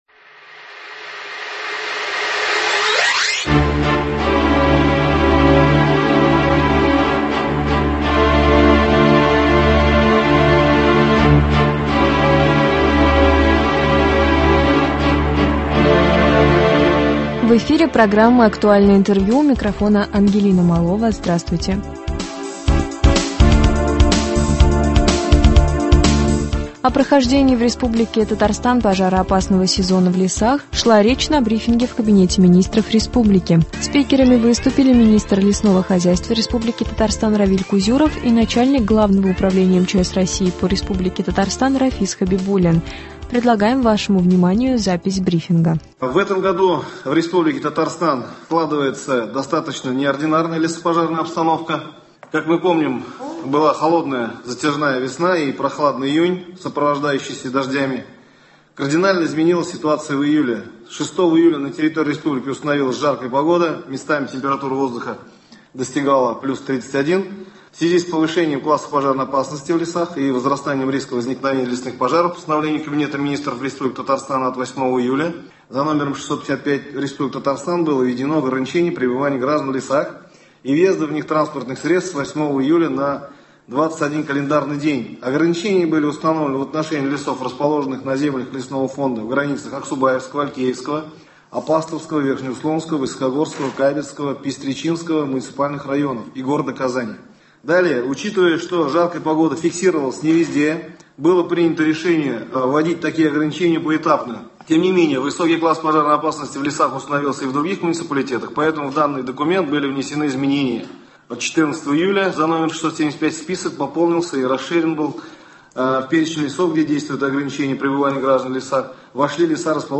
Актуальное интервью (17.08.22)